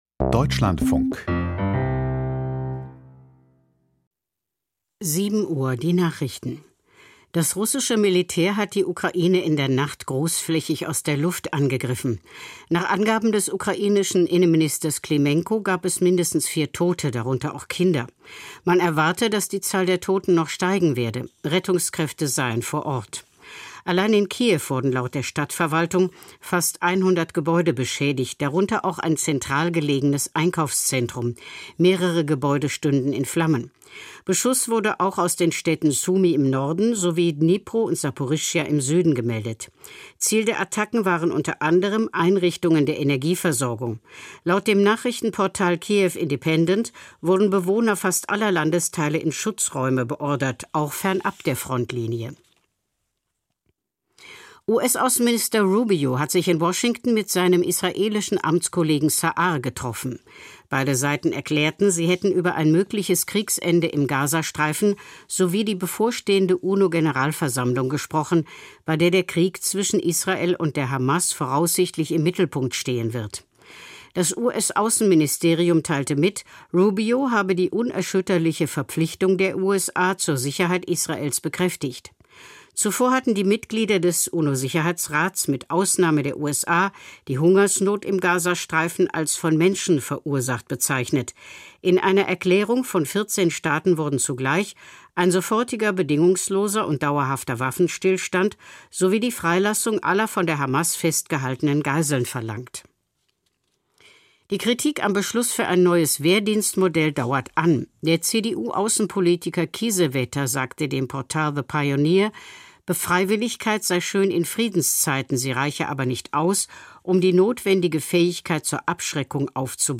Die Nachrichten vom 28.08.2025, 07:00 Uhr
Aus der Deutschlandfunk-Nachrichtenredaktion.